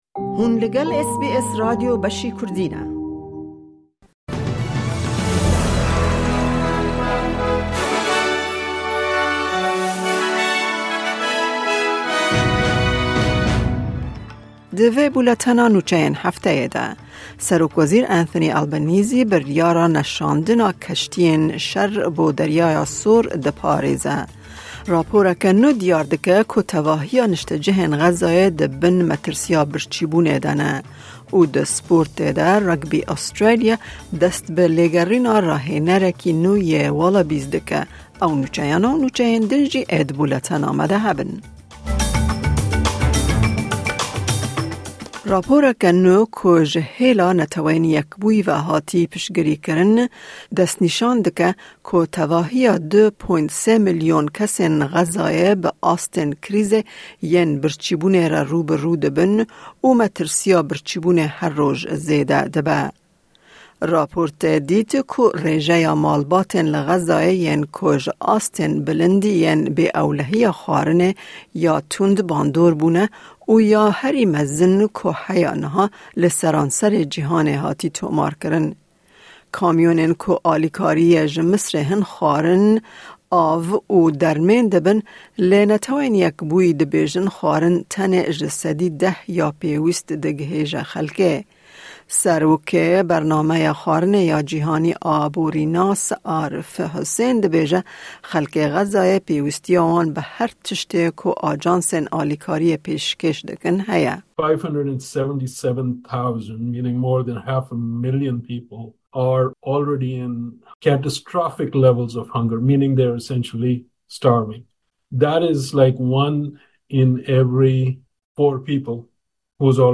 Nûçeyên Hefteyê